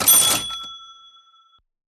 cash-sound.ogg